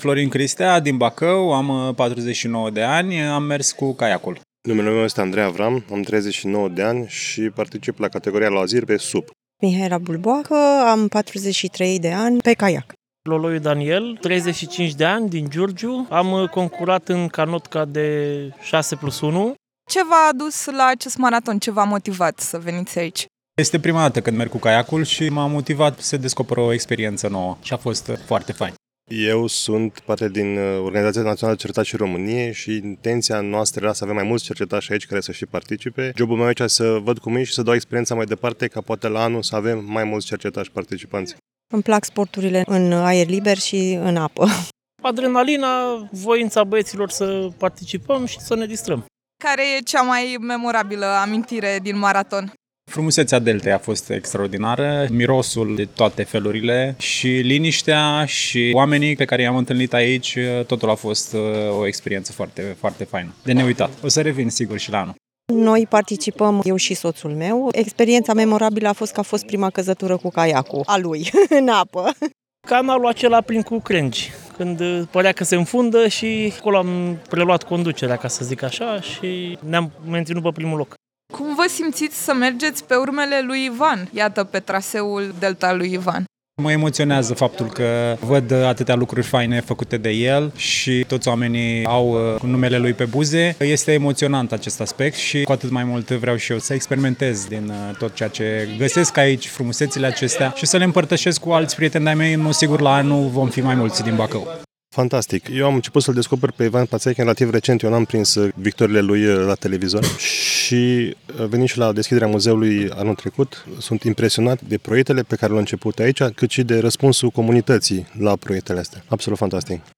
Iată cum a fost, în cuvintele celor care au pășit sau, mai bine zis, au vâslit prin Delta lui Ivan.